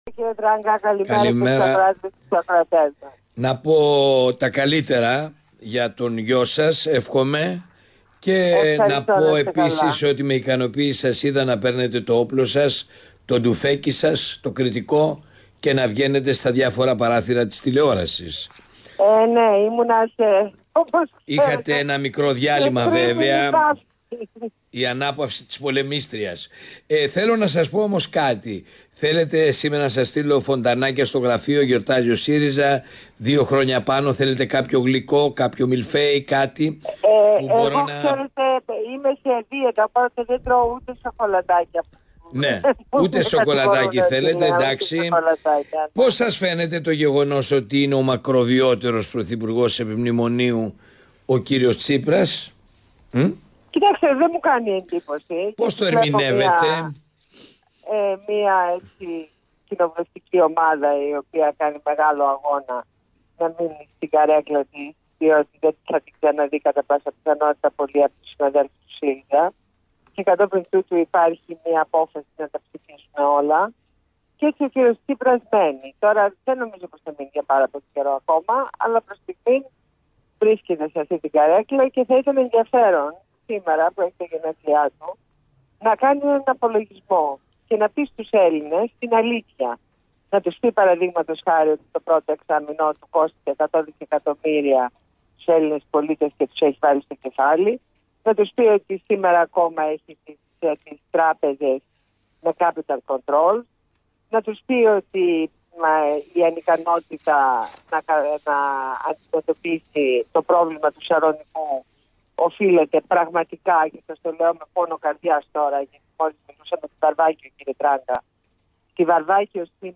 Συνέντευξη στο ραδιόφωνο Παραπολιτικά 90,1fm